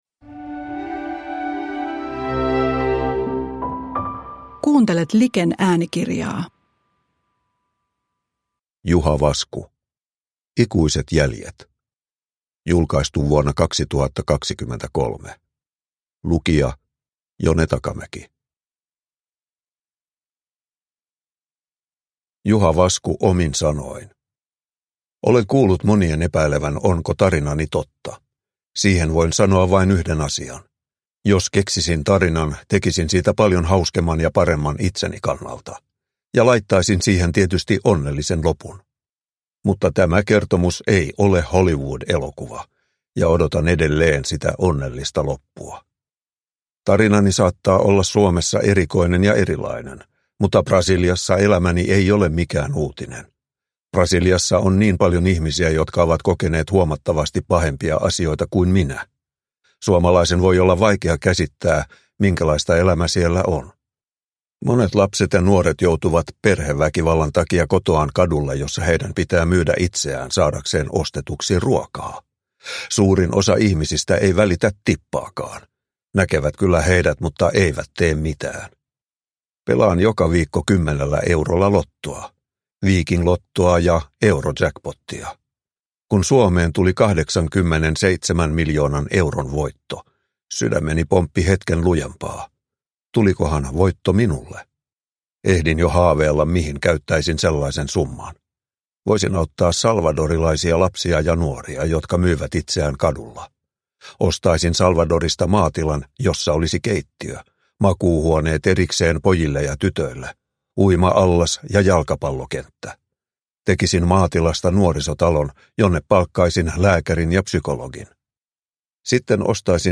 Ikuiset jäljet (ljudbok) av Juha Vasku